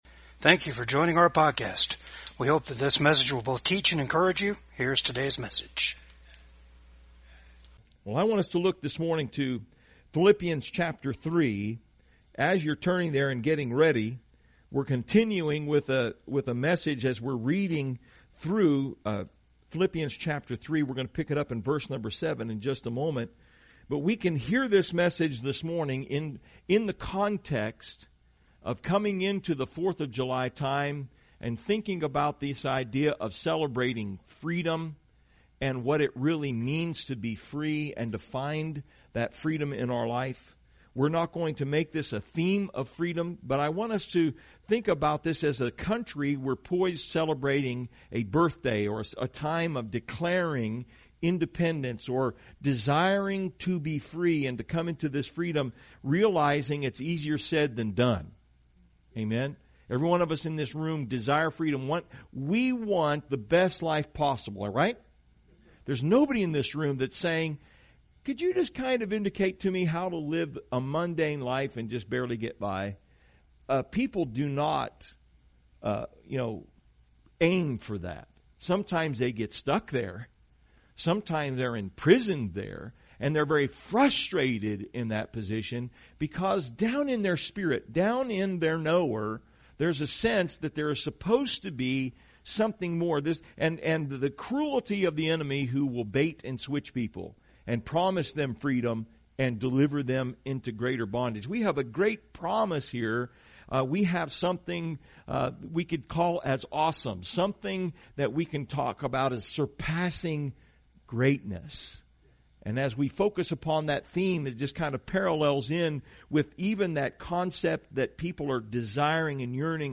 Philippians 3:7-11 Service Type: VCAG SUNDAY SERVICE THE SURPASSING GREATNESS OF CHRIST AND HIS RESURRECTION GIVES US FOUNDATIONAL STANDING BEFORE GOD.